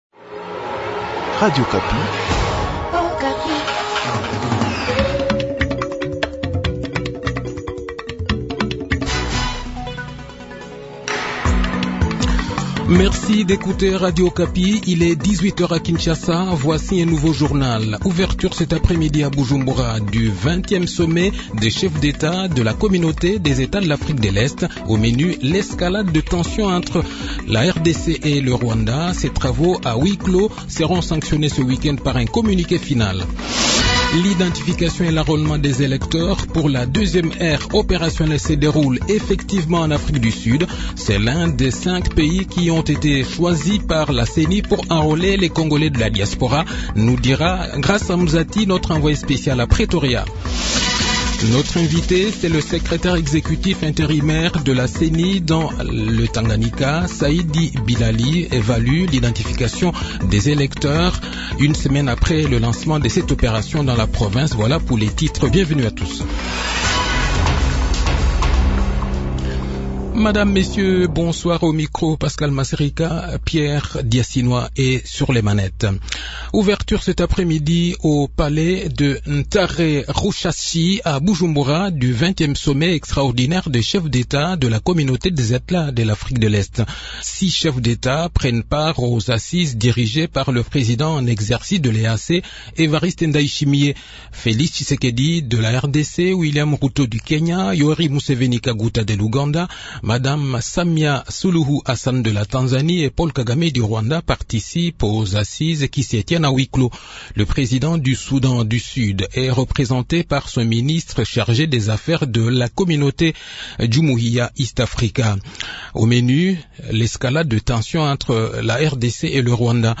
Journal Soir
e journal de 18 h, 4 février 2023